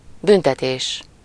Ääntäminen
US : IPA : ['pʌn.ɪʃ.mənt]